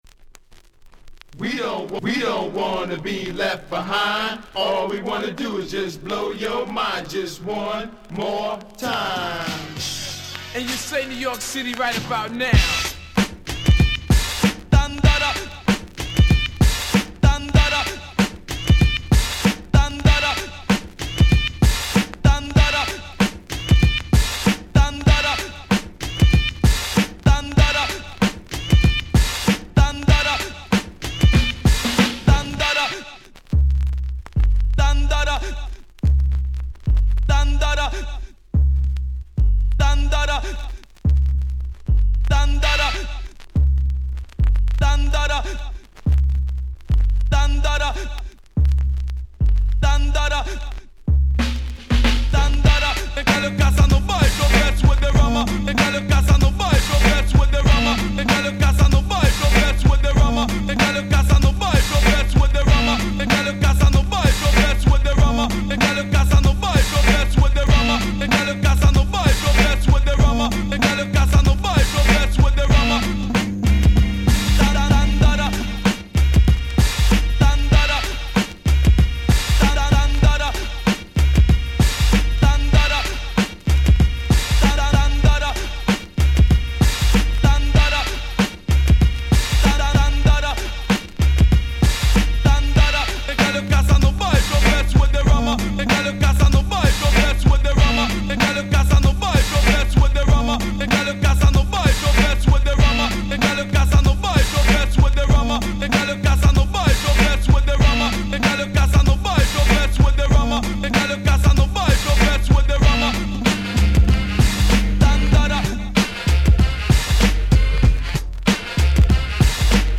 93' Nice Party Tracks.